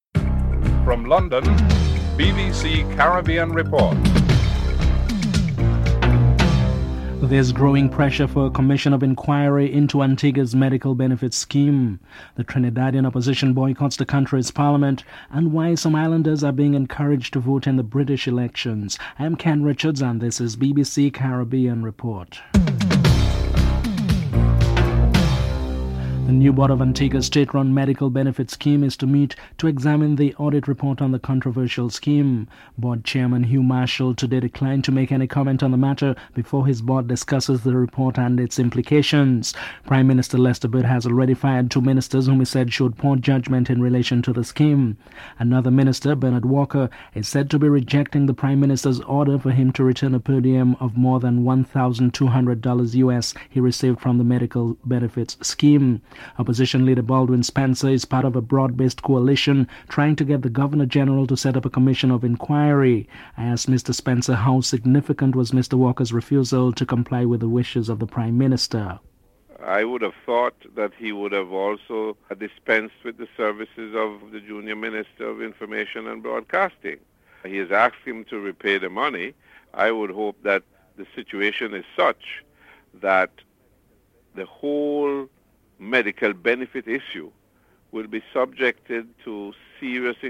Judge Albert Matthew is interviewed (07:04-10:21)